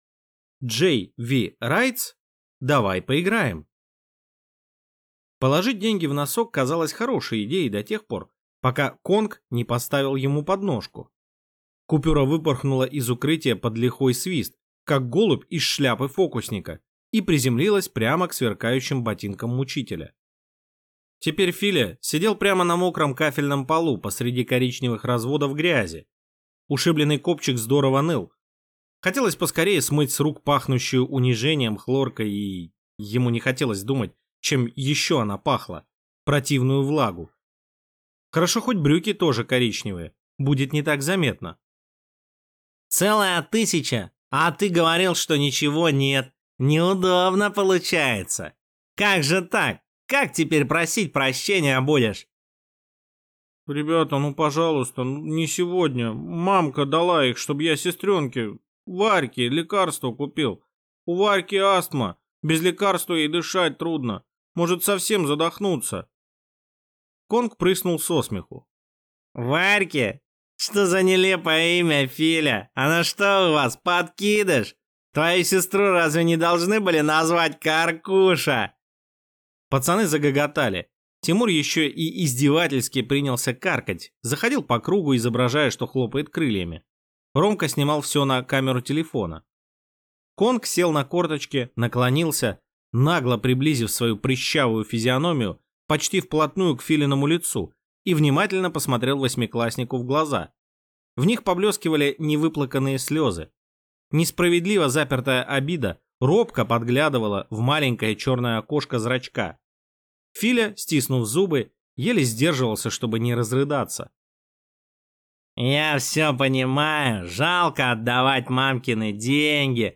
Аудиокнига Давай поиграем | Библиотека аудиокниг